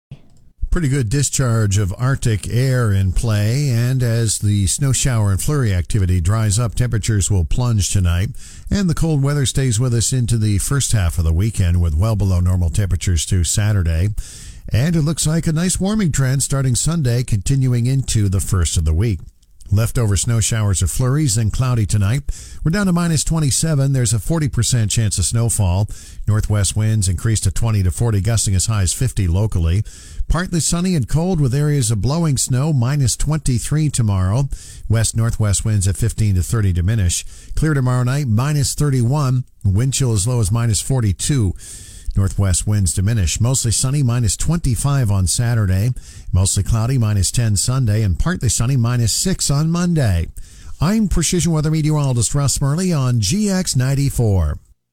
GX94 5:30pm Precision Weather Forecast – December 11, 2025